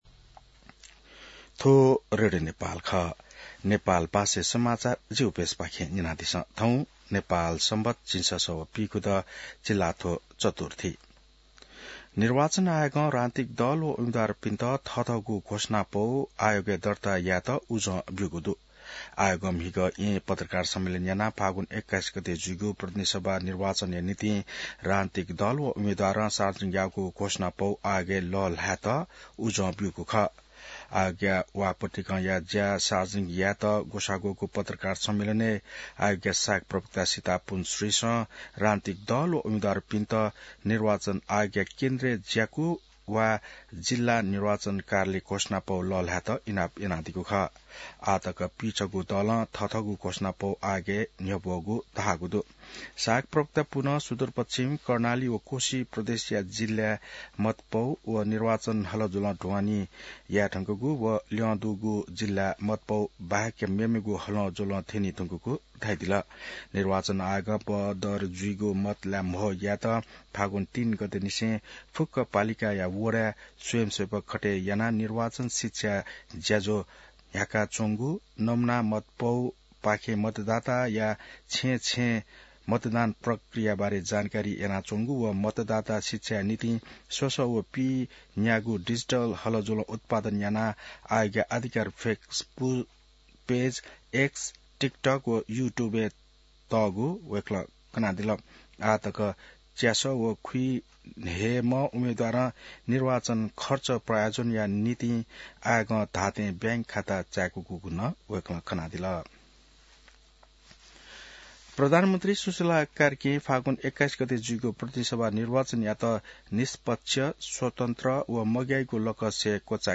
नेपाल भाषामा समाचार : ९ फागुन , २०८२